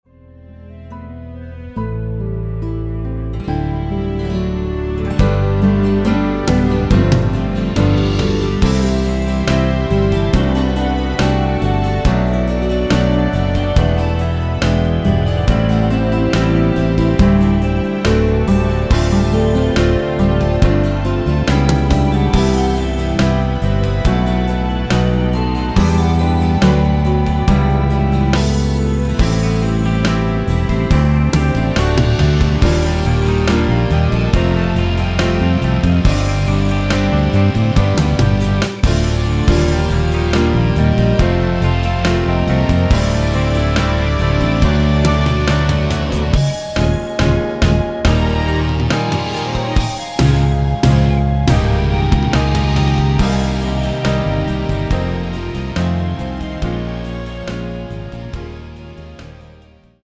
원키에서(-1)내린 짧은편곡 멜로디 포함된 MR입니다.(진행순서는 미리듣기및 가사 참조 하세요)
앨범 | O.S.T
◈ 곡명 옆 (-1)은 반음 내림, (+1)은 반음 올림 입니다.
앞부분30초, 뒷부분30초씩 편집해서 올려 드리고 있습니다.
중간에 음이 끈어지고 다시 나오는 이유는